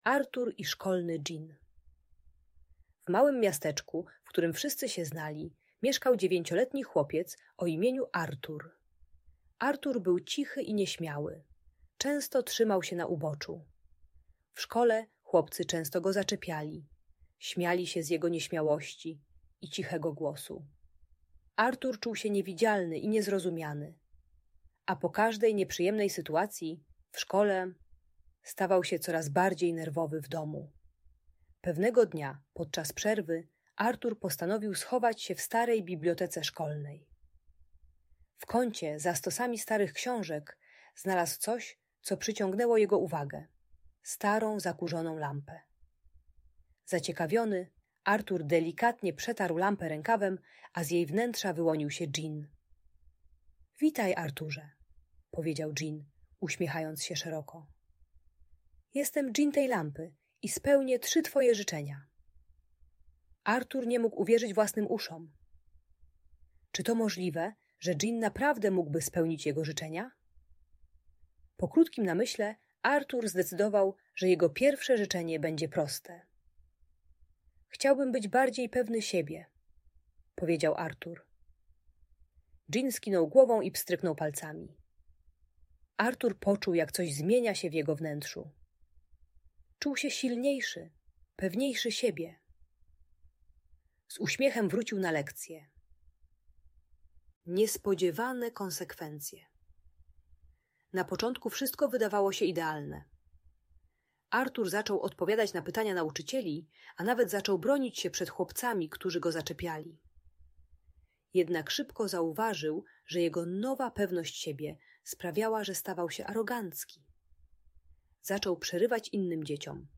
Artur i Szkolny Dżin - Szkoła | Audiobajka